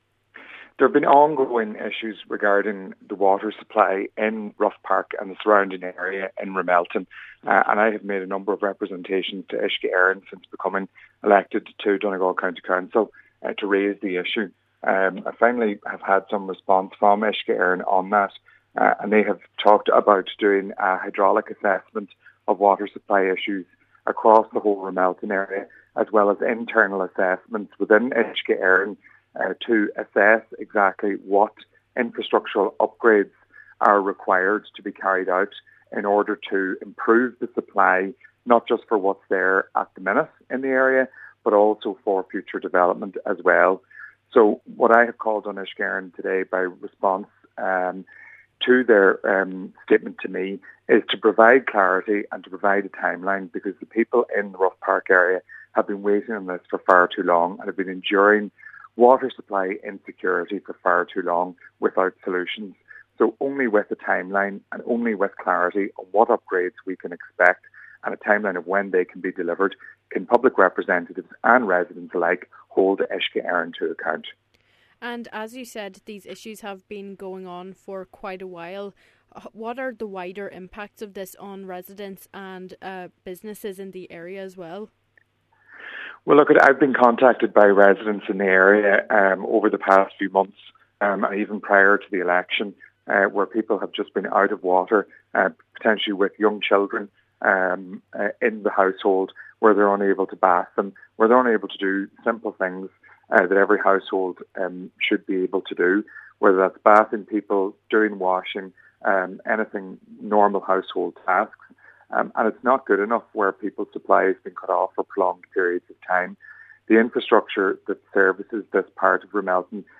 Cllr Meehan says residents desperately need an answer on what work will be required and when it will be done.